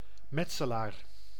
Ääntäminen
Synonyymit franc-maçon Ääntäminen France: IPA: [ma.sɔ̃] Haettu sana löytyi näillä lähdekielillä: ranska Käännös Ääninäyte Substantiivit 1. metselaar {m} Suku: m .